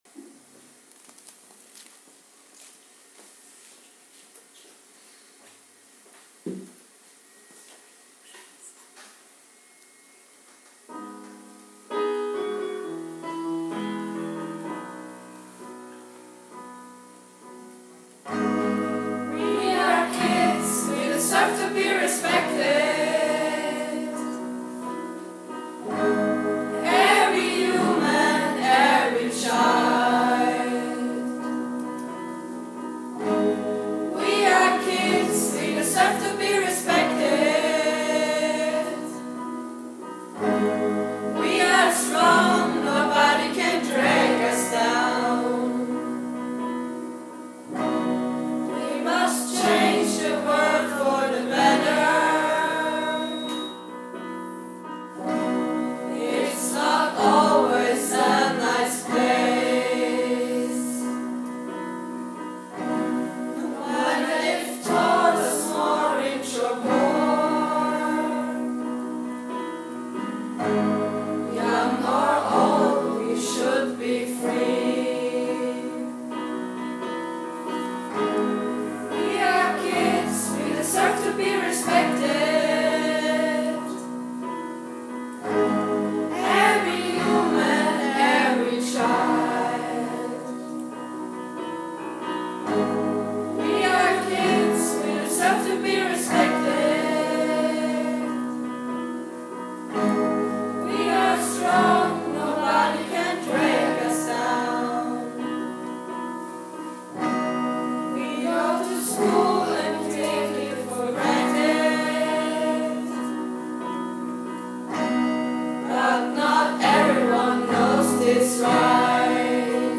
Die 4a Klasse der MS St. Ruprecht/Raab (22 Schülerinnen, 9 Burschen und 13 Mädchen zwischen 13 und 14 Jahre alt) hat seit der 1.Klasse sehr gerne an außerschulischen Herausforderungen teilgenommen.
Im Musikunterricht wurde dann nach einer passenden Melodie und Begleitung gesucht.